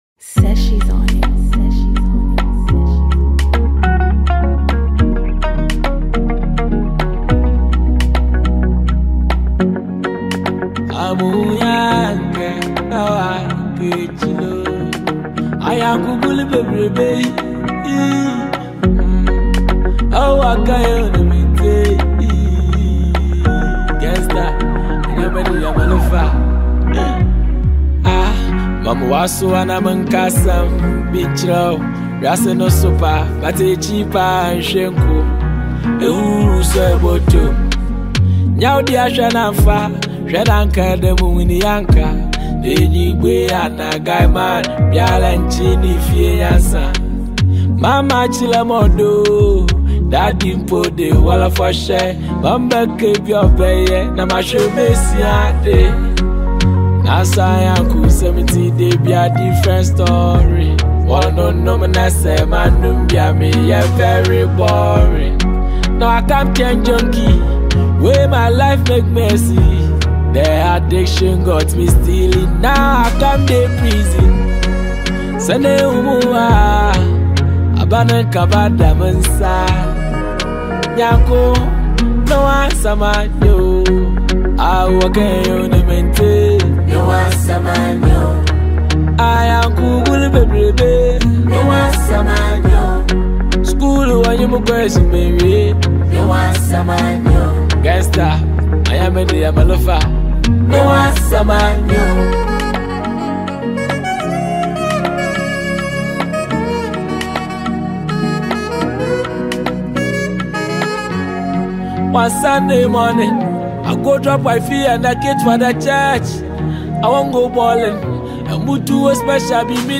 Highlife Ghanaian songwriter